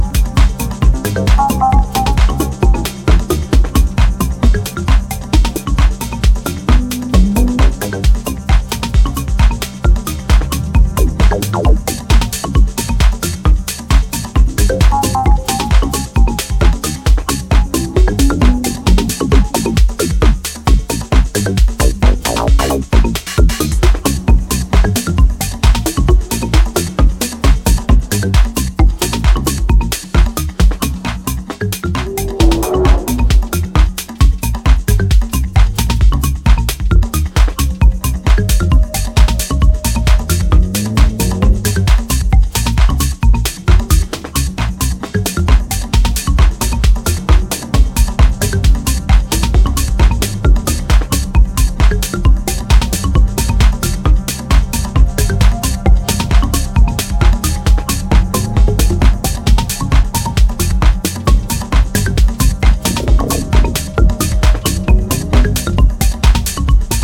this is a dance record!